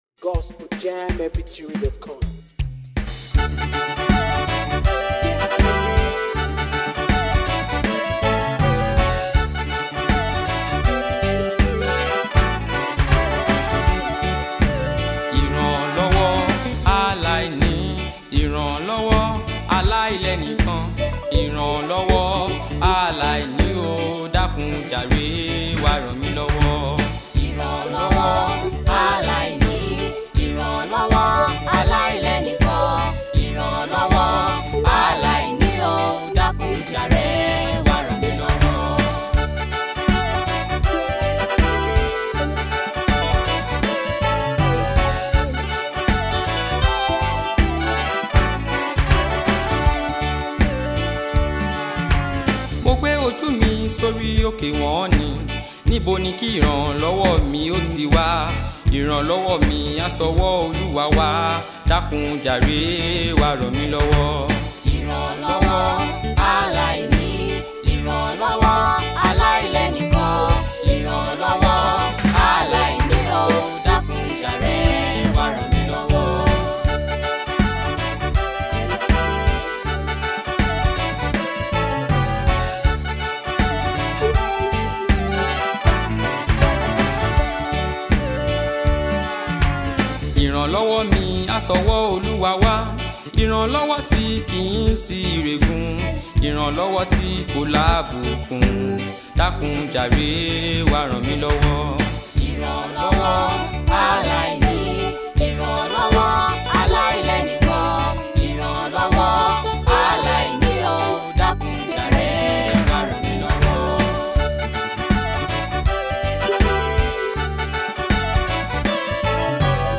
Fast rising Gospelj singer
new heart touching song